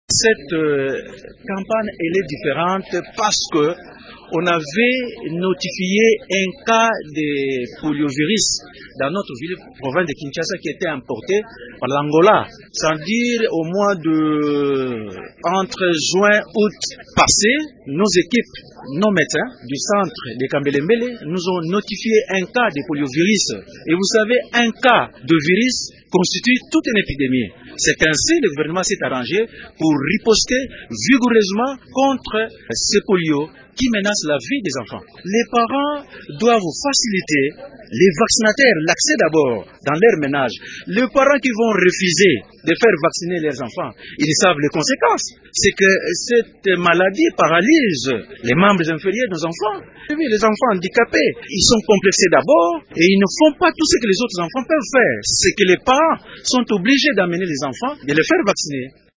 Il l’a expliqué à Radio Okapi: